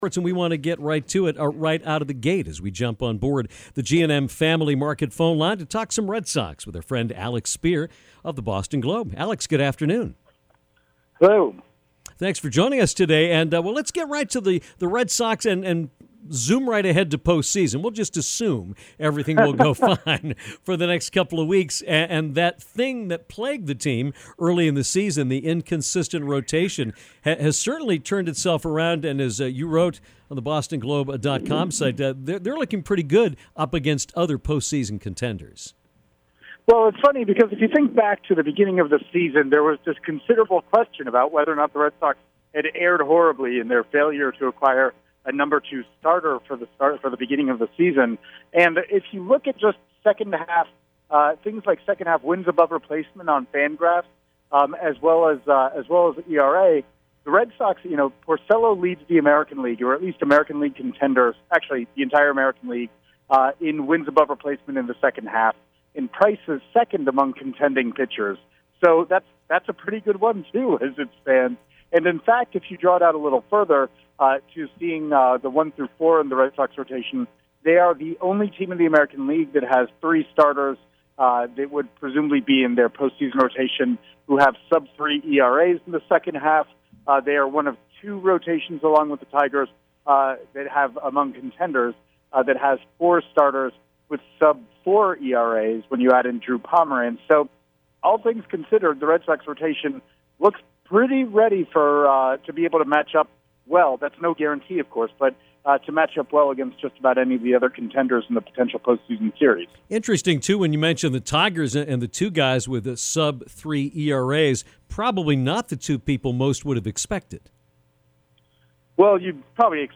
check out this fascinating conversation from the ultimate insider. https